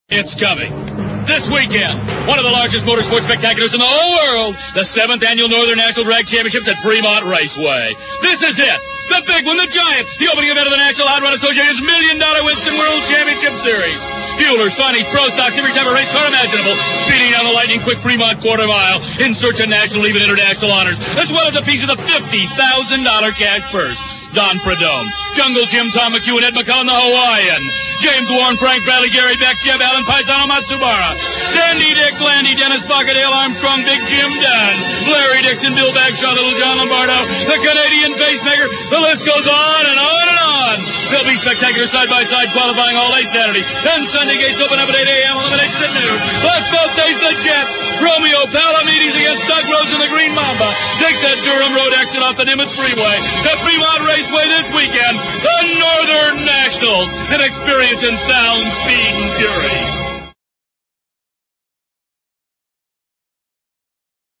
Drag Strip Radio Spots